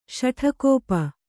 ♪ śaṭhakōpa